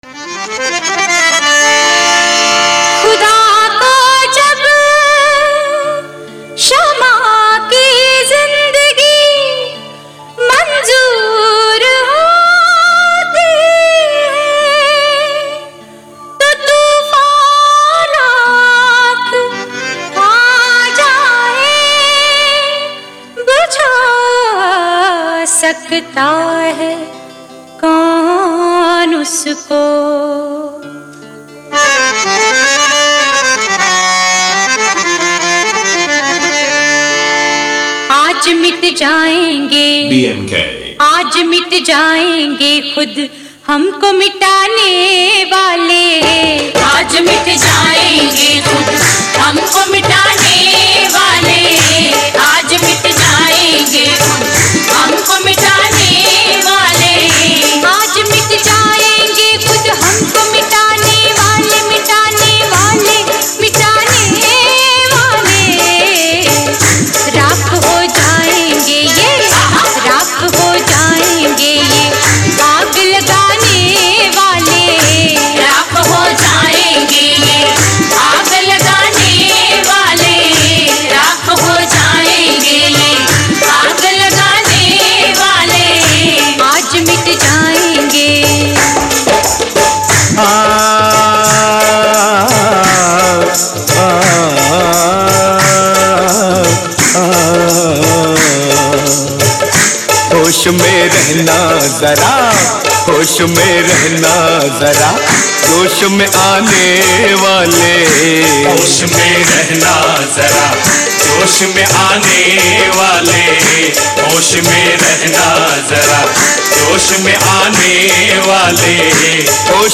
Mela Competition Filter Song